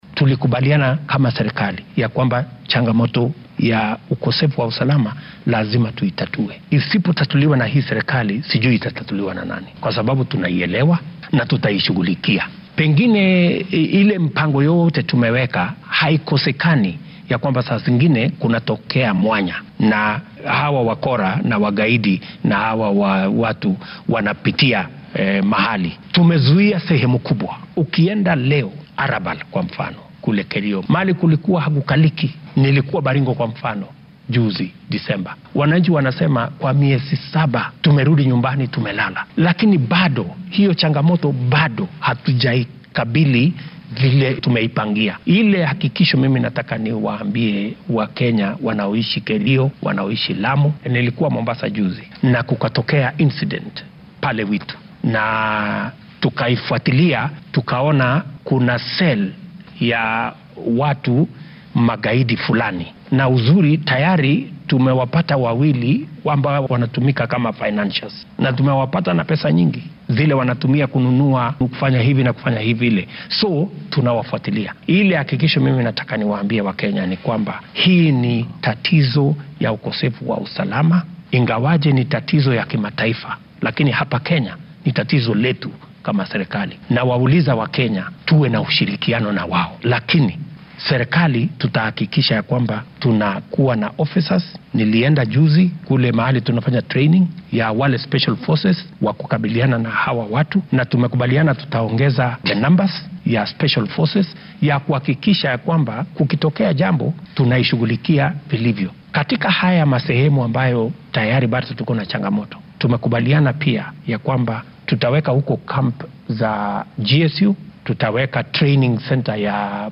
Madaxweynaha dalka William Ruto oo xalay fiidkii xarunta madaxtooyada ee State House ee magaalada Nairobi si wadajir ah wareysi ugu siiyay telefishinnada